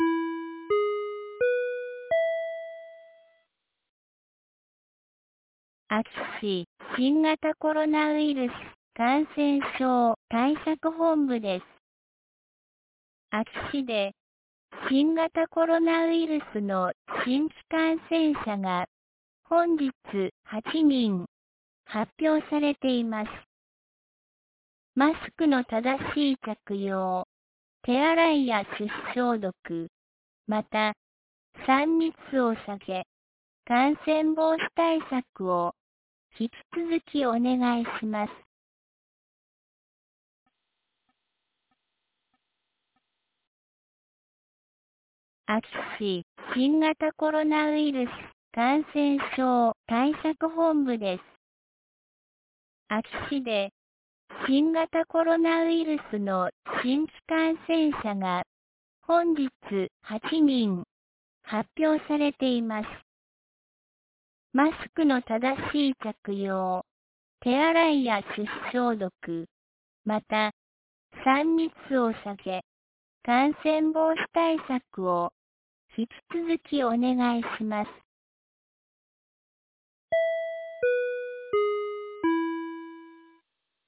2022年09月14日 17時06分に、安芸市より全地区へ放送がありました。